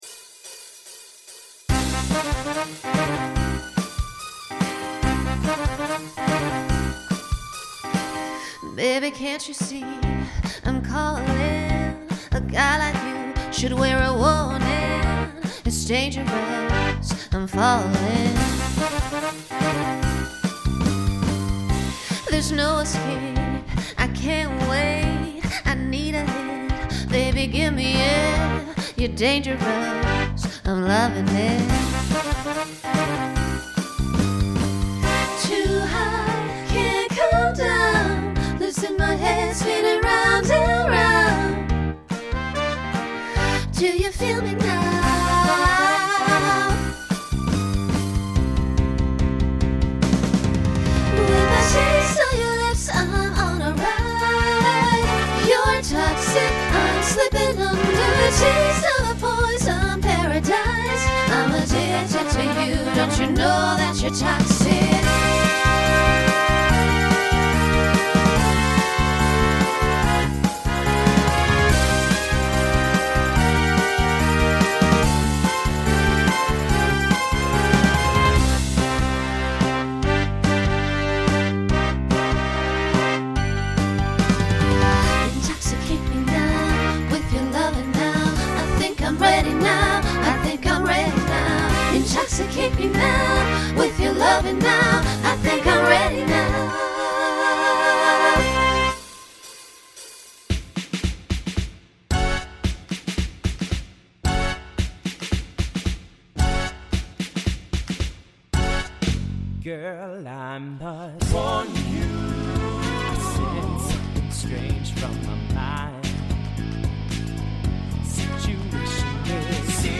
Genre Pop/Dance
Transition Voicing Mixed